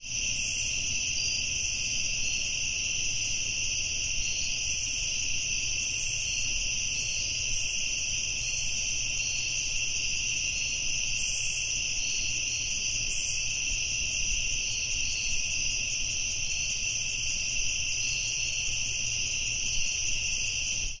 nightime.ogg